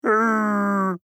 AV_bear_howl.mp3